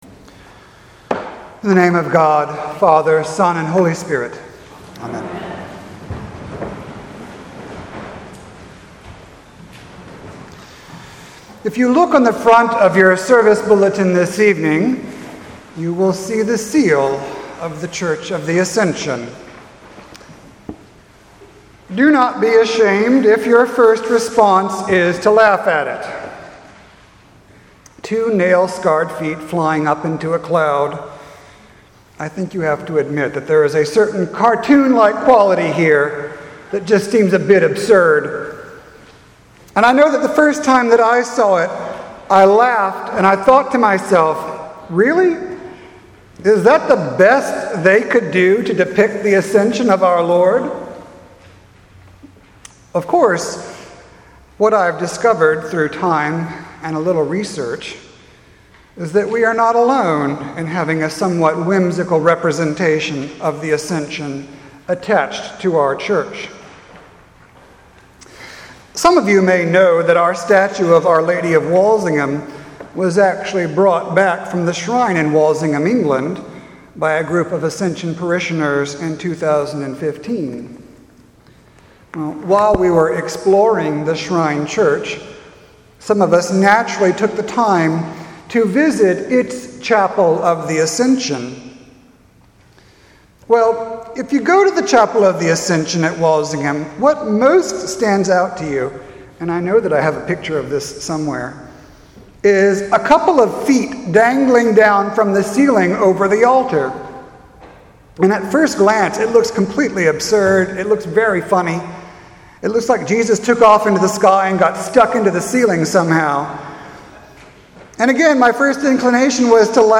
sermon-5-10-18.mp3